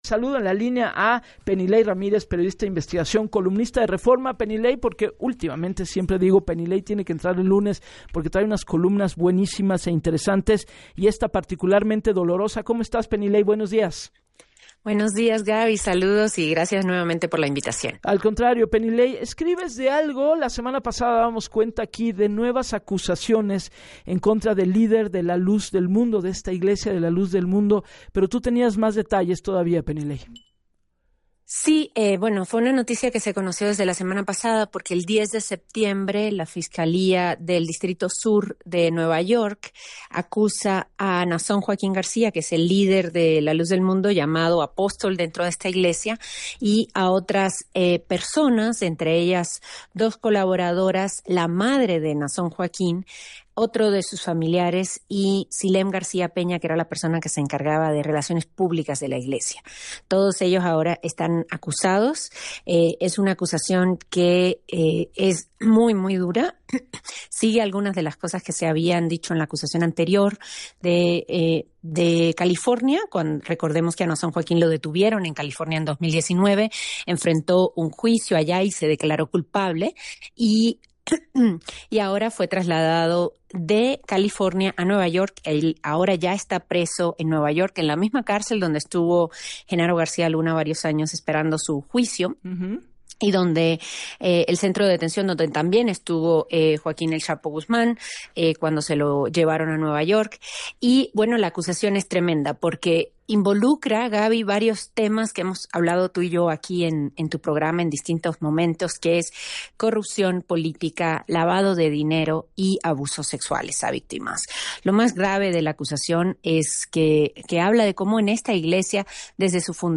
En entrevista con Gabriela Warkentin, la periodista recordó que Nasón Joaquín, líder de la Luz del Mundo, fue detenido en California, pero ahora al igual que otro criminal como Genaro García Luna, ya se encuentra en Nueva York en donde enfrente una acusación que involucra temas como la corrupción política lavado de dinero y abuso sexual, una actividad heredada.